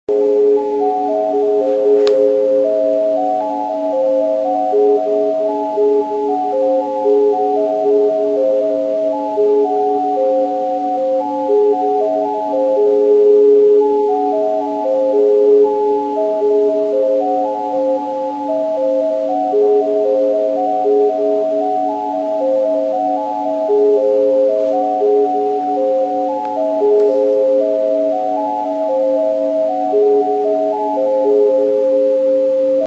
nature » creek long
描述：A recording from a small creek behind my house.
标签： birds ambient water am nature fieldrecording
声道立体声